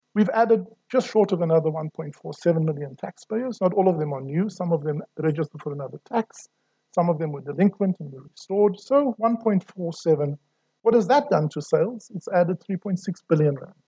Kieswetter, speaking during an online event hosted by Deloitte, says SARS is constantly at work to improve its technical capabilities in order to deliver better services to taxpayers.